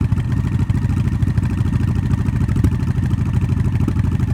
Engine_loop_4.ogg